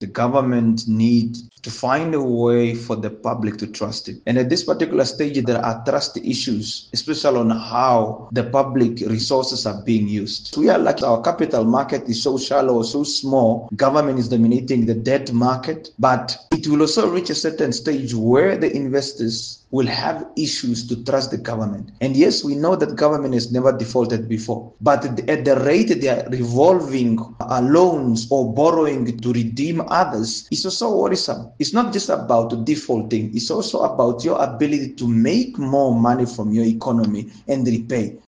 Nuus